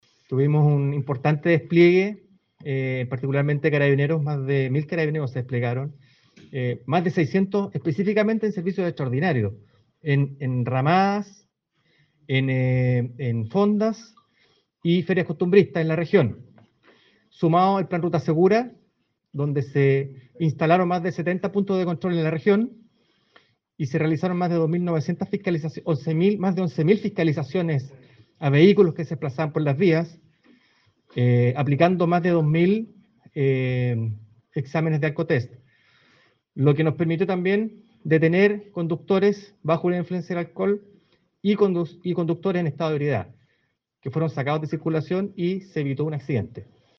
En tanto, el general Carlos López, a cargo de la décima zona de Carabineros Los Lagos, coincidió con este resultado a subrayó la importancia del despliegue de los efectivos en todas las comunas.